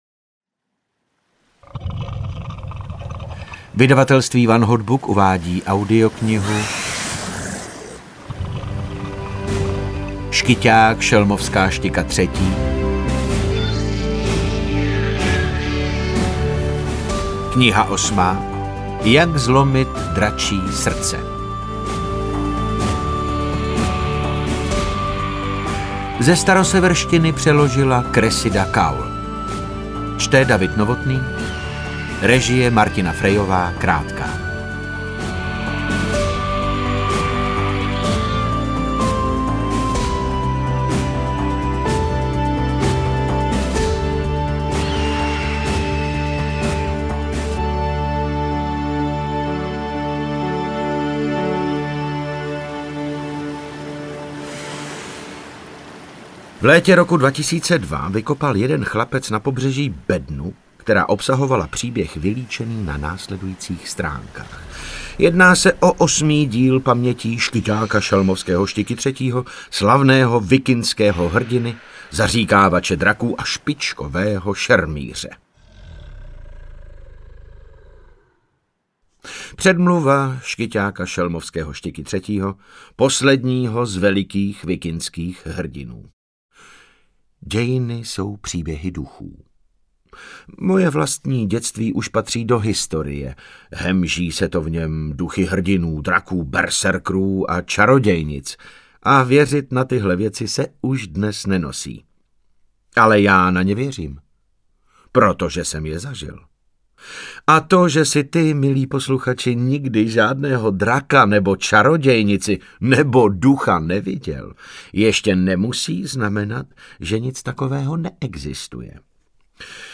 Interpret:  David Novotný